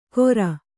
♪ kora